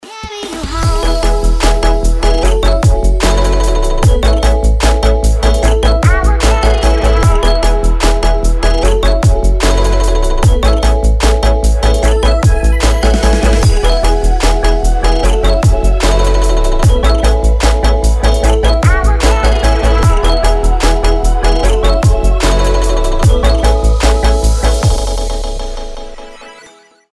• Качество: 320, Stereo
мелодичные
Electronic
future house
приятные
звонкие
электронные